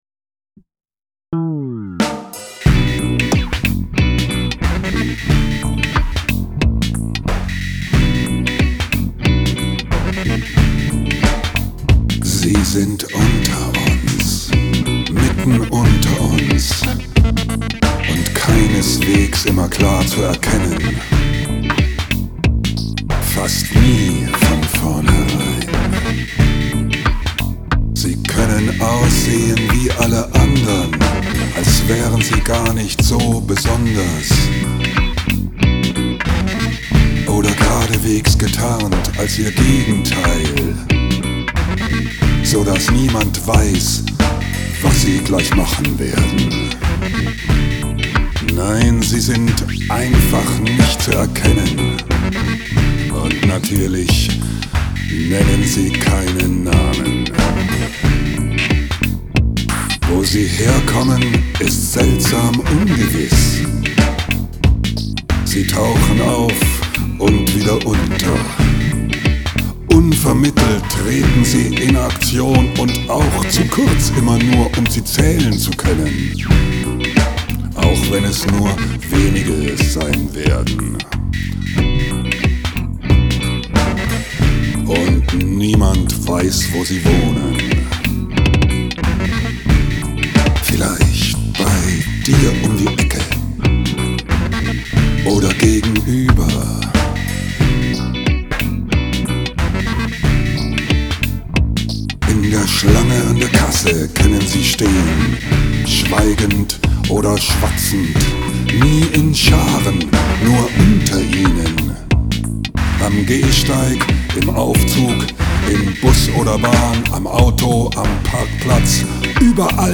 Skizze für „february album writing month“ FAWM2018: Drumcomputer, Drumloop, E-Gitarre, Fretless Bass, Synthesizer, Sprache.